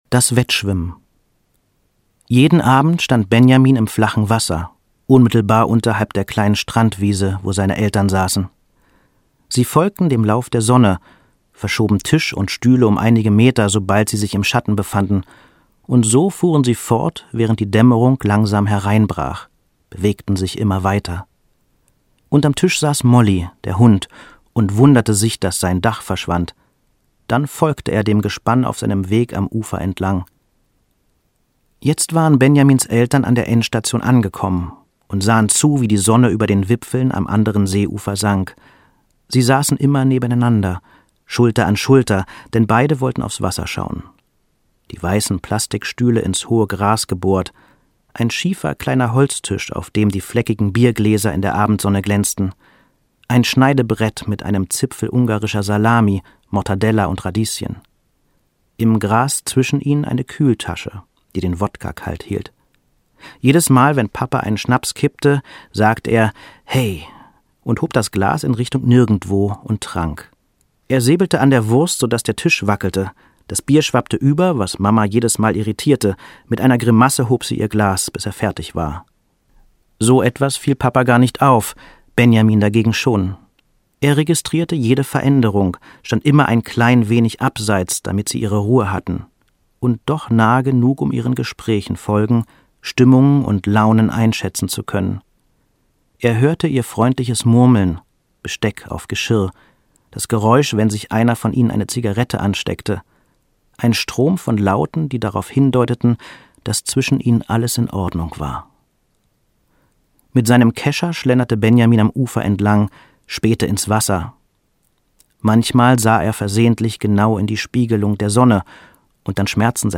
Die Überlebenden Alex Schulman (Autor) Fabian Busch (Sprecher) Audio Disc 1 MP3-CD (6h 16min) 2021 | 1.